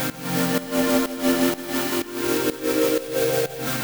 GnS_Pad-alesis1:4_125-C.wav